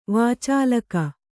♪ vācālaka